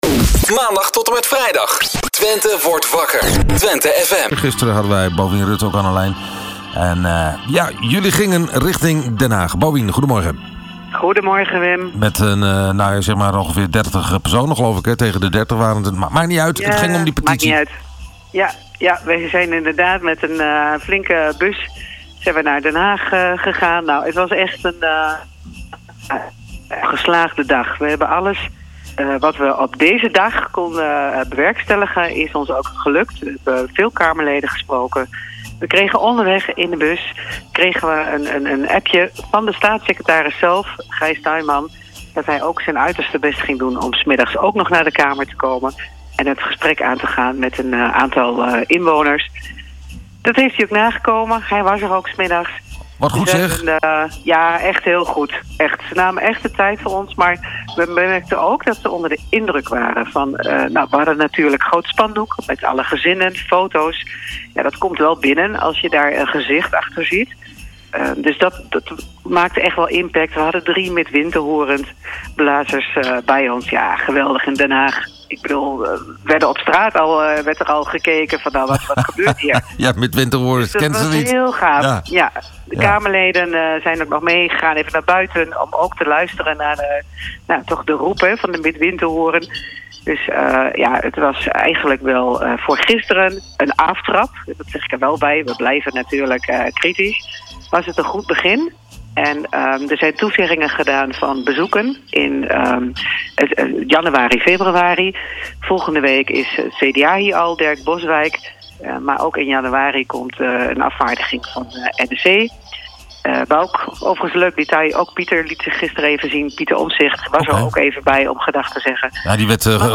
Onderweg was er nog gelegenheid voor een zoom gesprek vanuit de bus rechtstreeks in de uitzending van RTV Oost (zie de link hieronder).